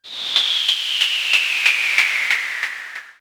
Machine02.wav